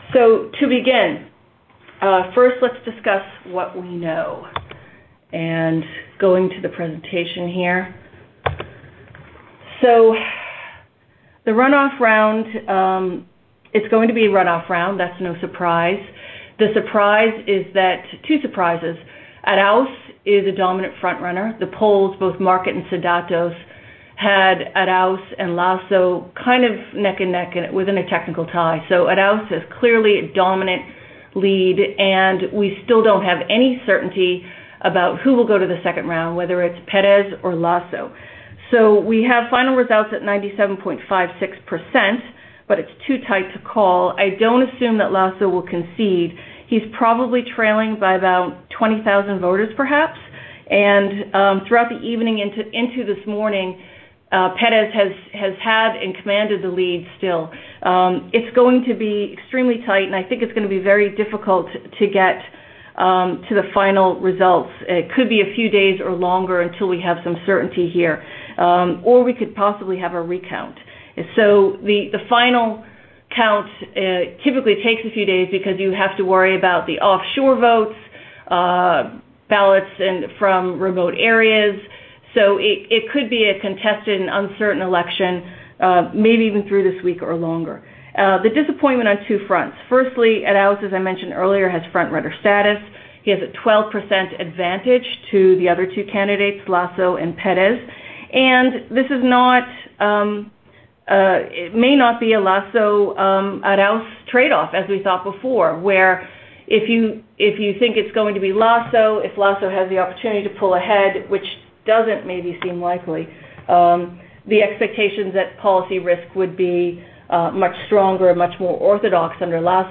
Strategy Conference Call